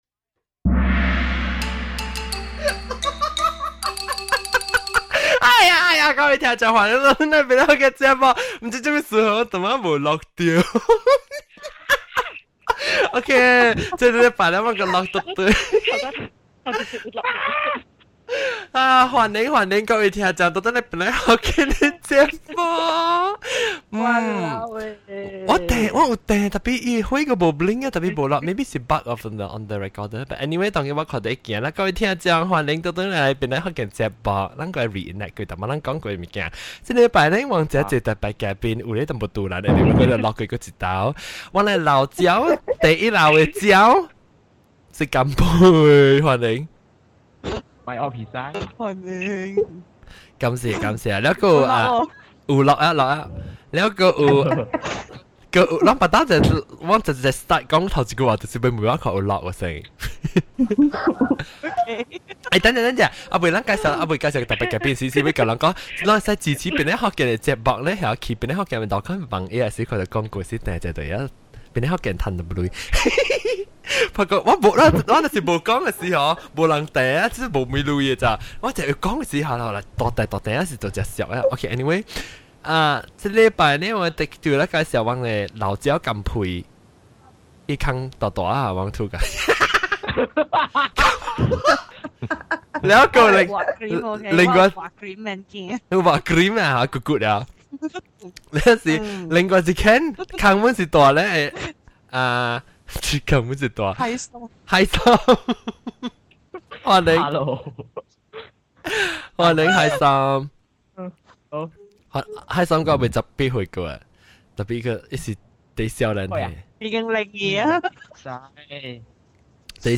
Find out which guest has a super laugh!
wondering, why the quality so bad liao? lol